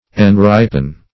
enripen - definition of enripen - synonyms, pronunciation, spelling from Free Dictionary Search Result for " enripen" : The Collaborative International Dictionary of English v.0.48: Enripen \En*rip"en\, v. t. To ripen.